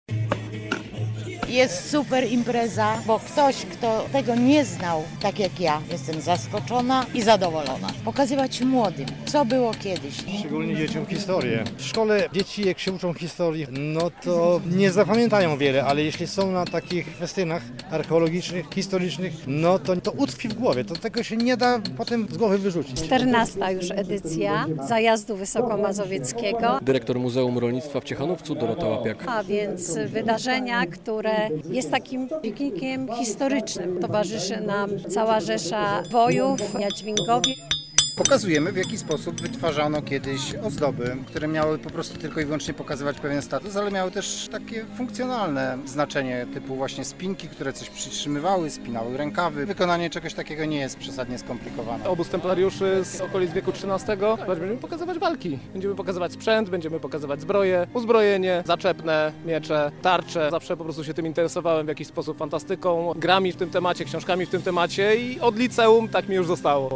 W Muzeum Rolnictwa w Ciechanowcu rozpoczął się Zajazd Wysokomazowiecki - relacja